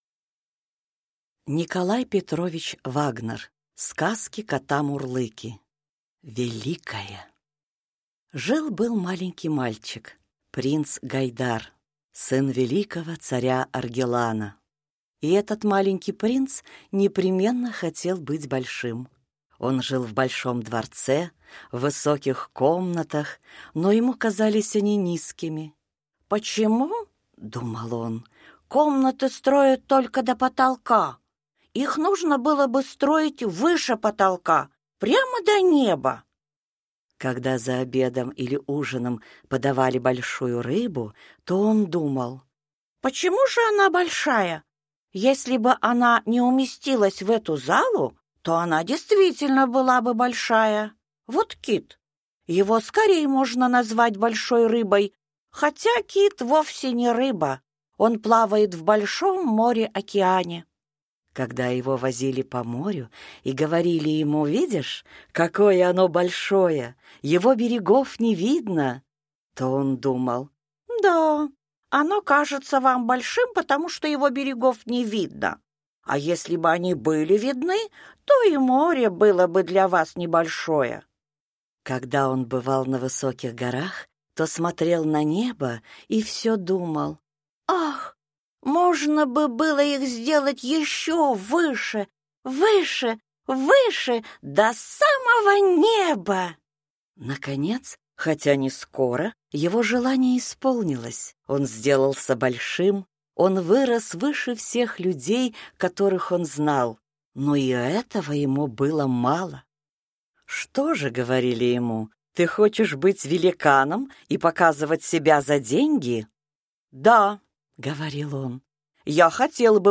Аудиокнига Великое | Библиотека аудиокниг